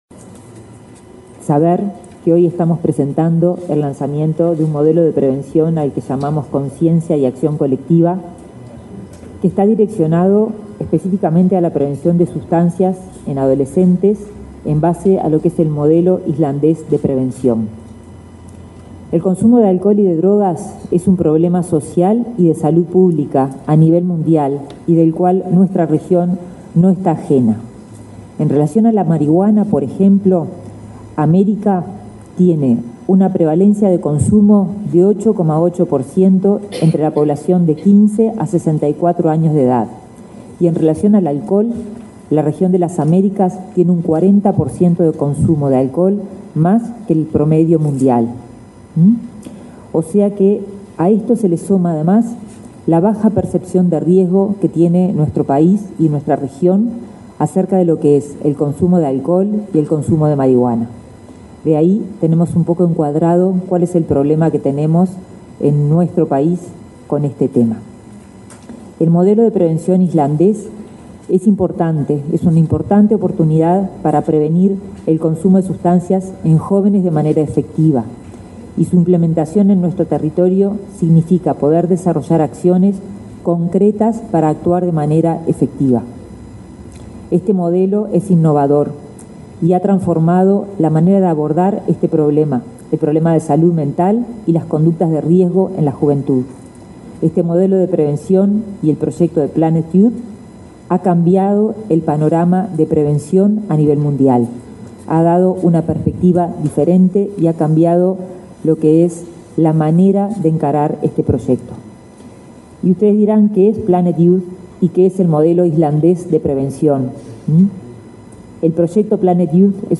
Palabras de autoridades en acto en el MSP
En la oportunidad, se expresaron la titular y el subsecretario de la cartera, Karina Rando y José Luis Satdjian; la subdirectora general de Salud, Jacquelin Coronato, y la consejera de la Administración Nacional de Educación Pública Dora Graziano.